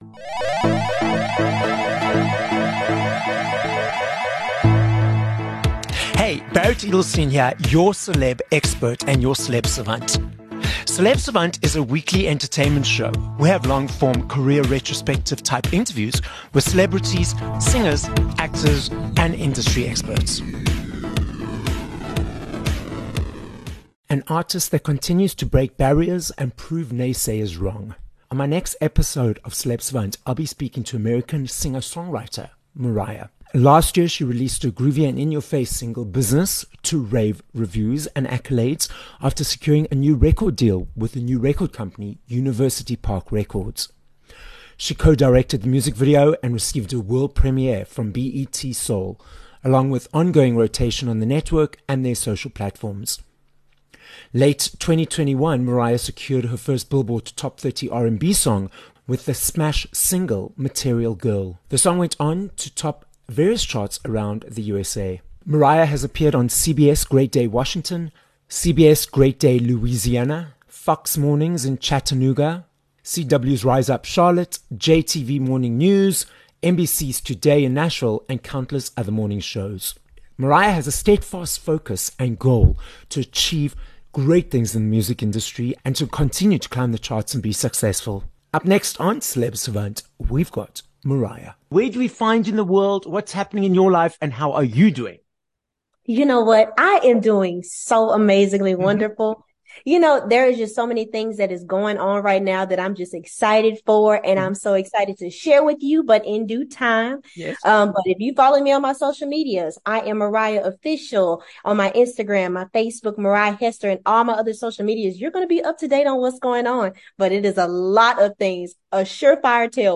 15 May Interview